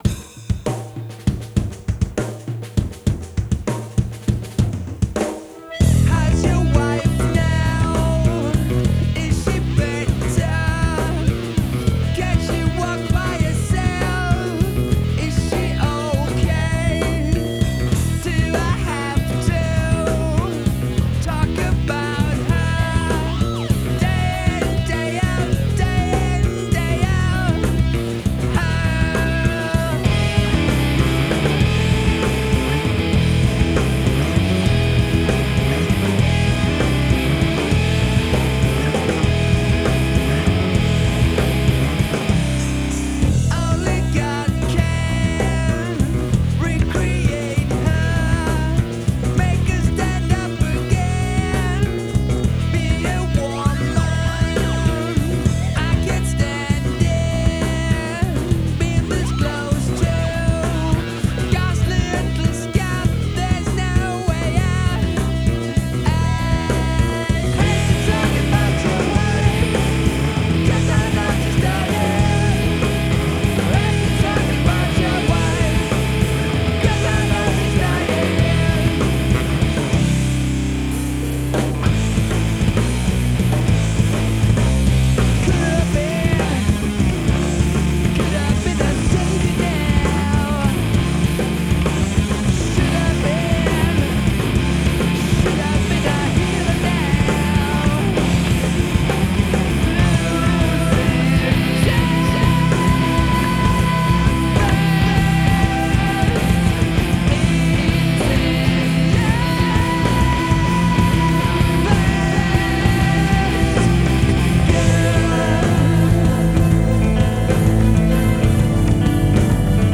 PopmusikkRock
Pop-rock band fra Sola.
gitar, vokal
bass
trommer